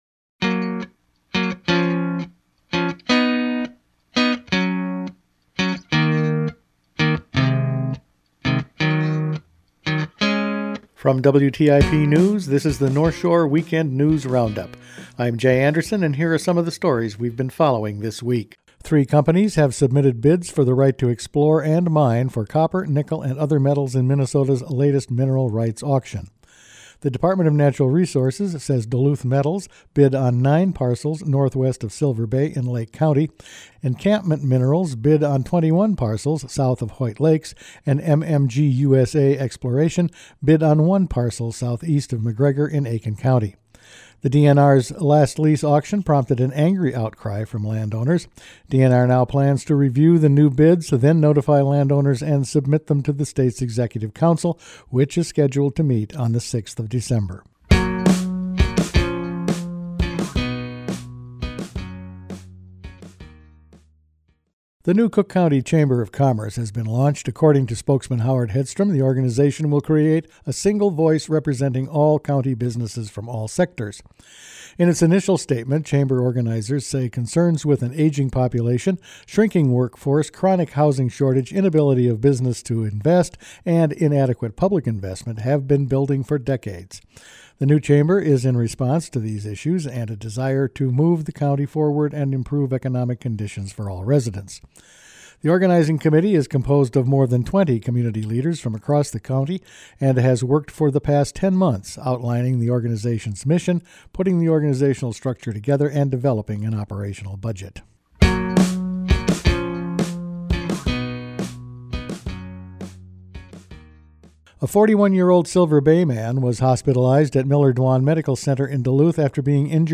Weekend News Roundup for October 27
Each weekend WTIP news produces a round up of the news stories they’ve been following this week. The state minerals exploration leases continues, Cook County has a new Chamber of Commerce, there was an explosion at Tac Harbor, the moose hunt was down this year, and much more…all in this week’s news.